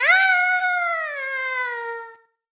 toadette_fall.ogg